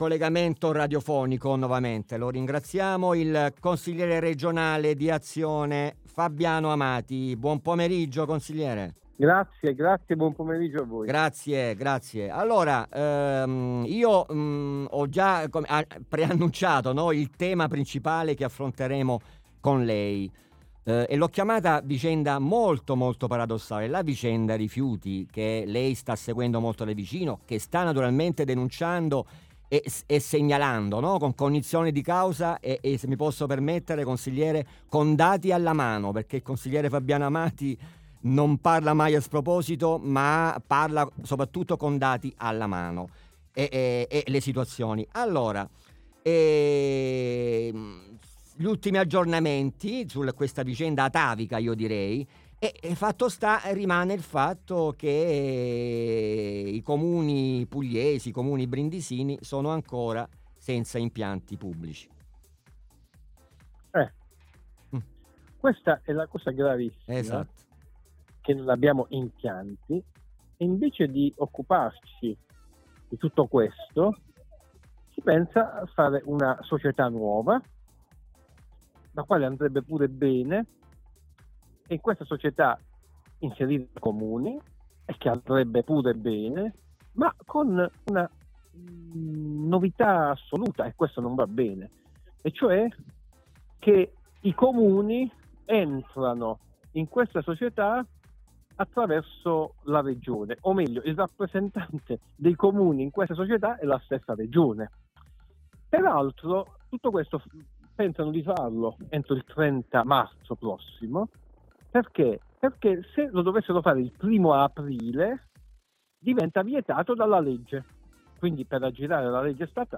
SPAZIO APERTO con il consigliere regionale Fabiano Amati e l'On. Luciana Sbarbati
Interviste SPAZIO APERTO con il consigliere regionale Fabiano Amati e l'On.